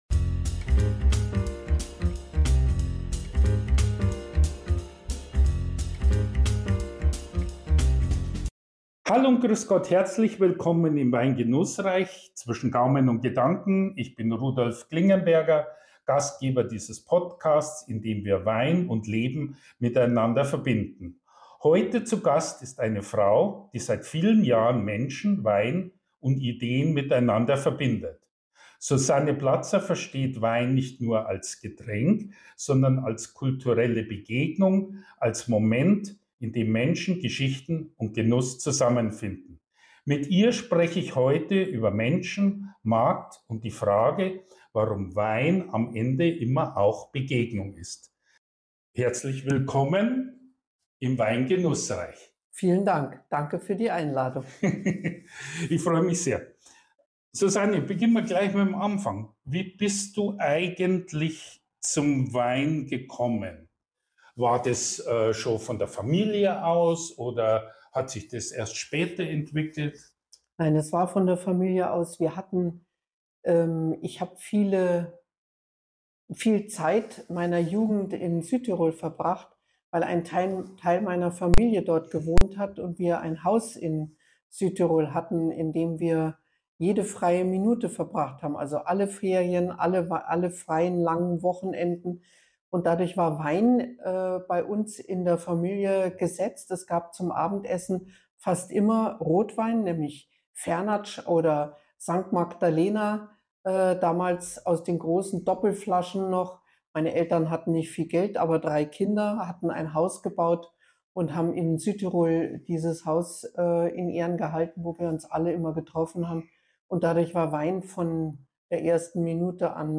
Es geht um mehr als Geschmack: Über Herkunft, Haltung, Marktmechanismen und die Frage, wie viel Persönlichkeit in einem Wein steckt. Ein Gespräch zwischen Praxis, Erfahrung und der Überzeugung, dass Wein immer auch Begegnung ist.